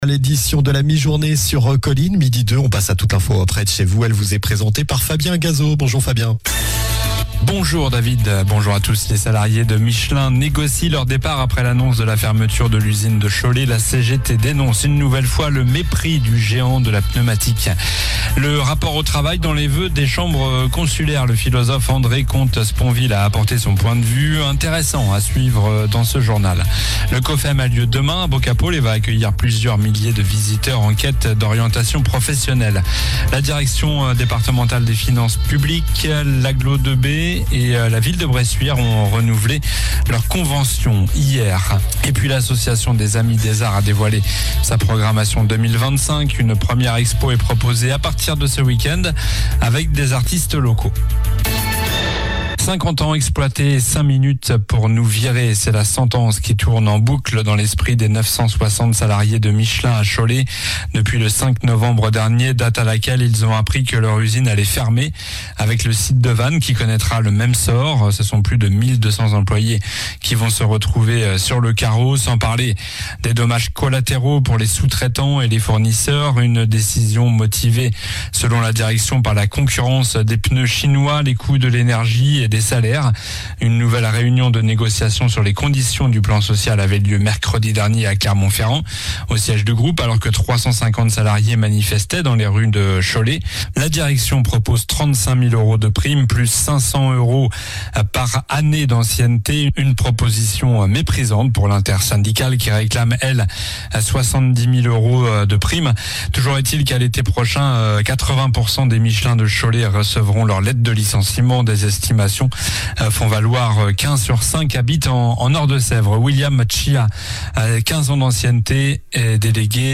Journal du vendredi 24 janvier (midi)